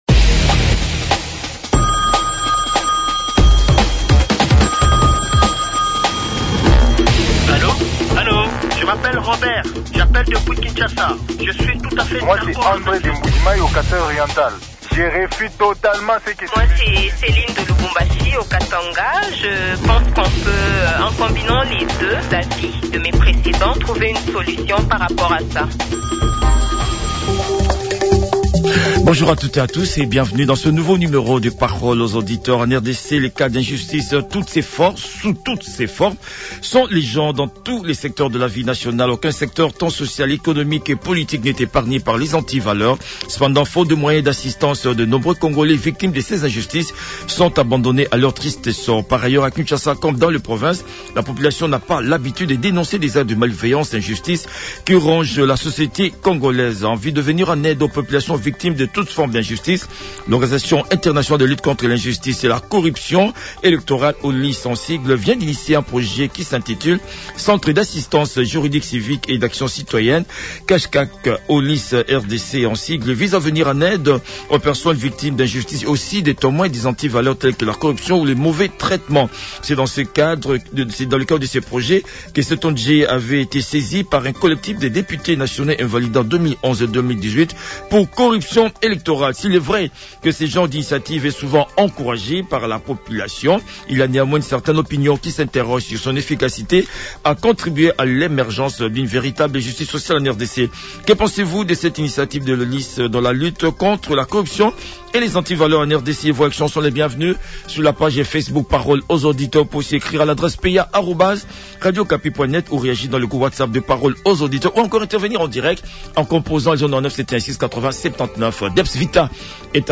débattent de ce sujet avec les auditeurs sous la modération de